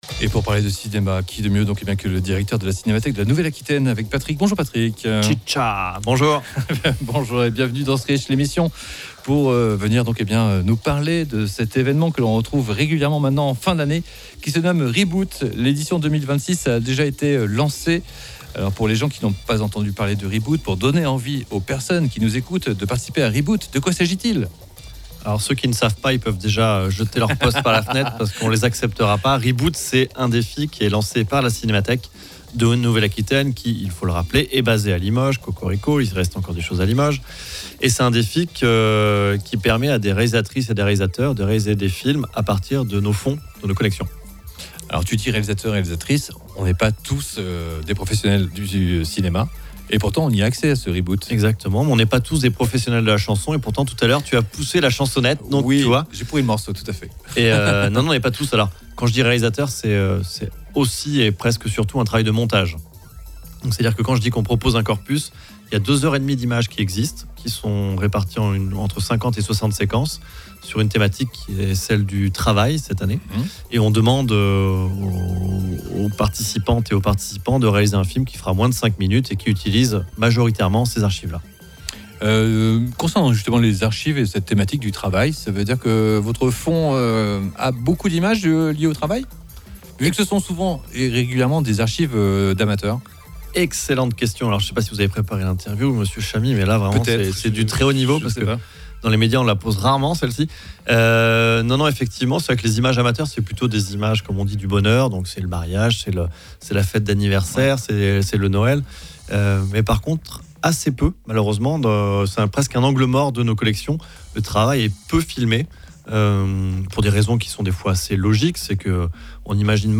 itw-rebboot.mp3